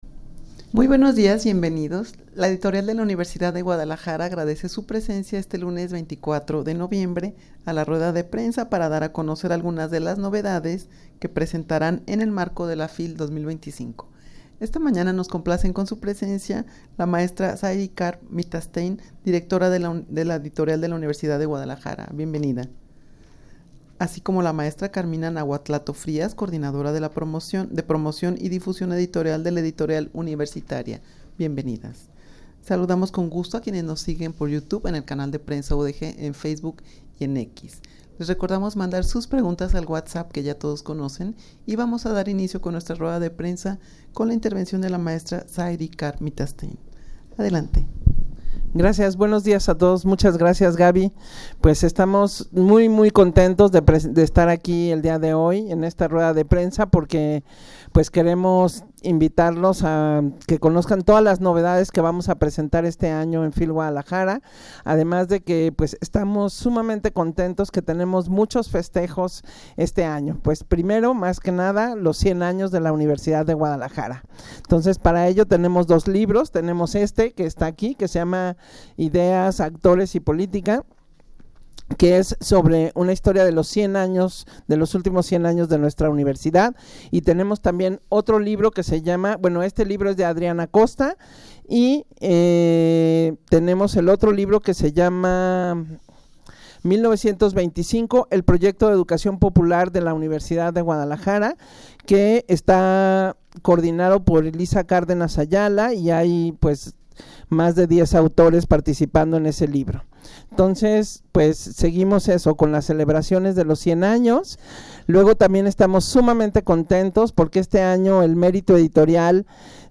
rueda-de-prensa-para-dar-a-conocer-algunas-de-las-novedades-que-presentaran-en-el-marco-de-la-fil-2025_0.mp3